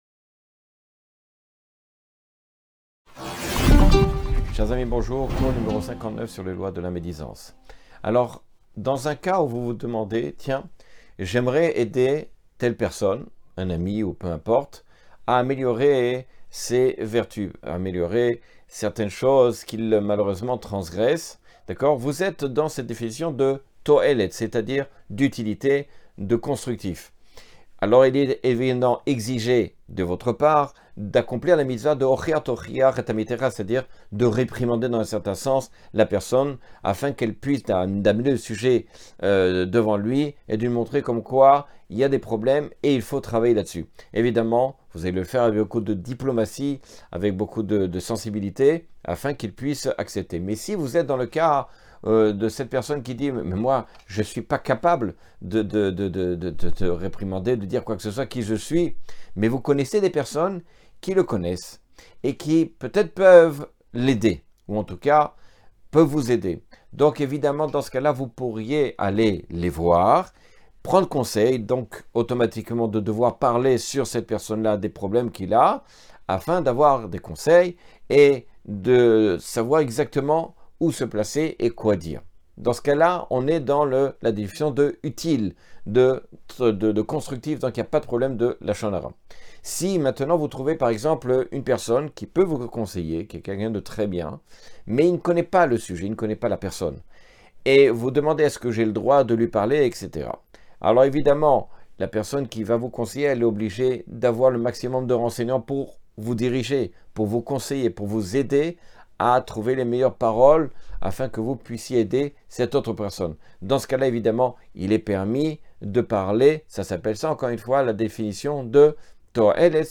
Cours 59 sur les lois de lashon hara.